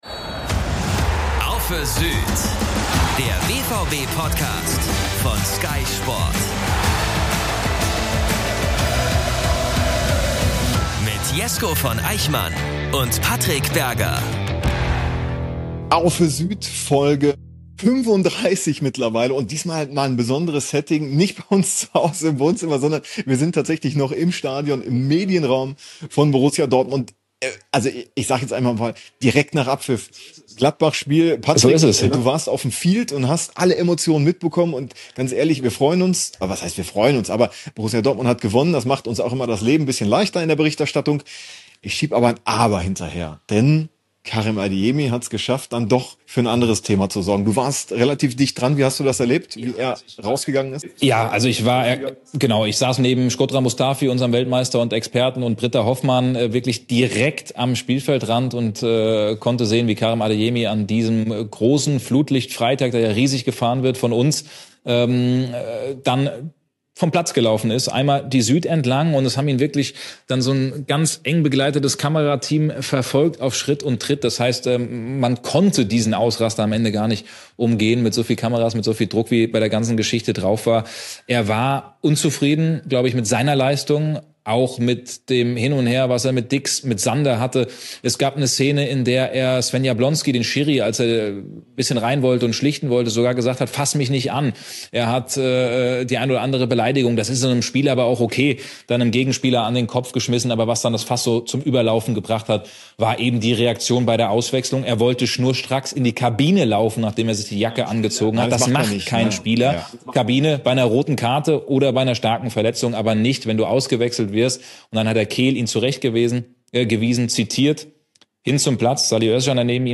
Beschreibung vor 21 Stunden Borussia Dortmund gewinnt 2:0 gegen Gladbach und geht mit einem Sieg in die Winterpause – trotzdem bleibt Unruhe. Direkt nach Abpfiff im Stadion ordnen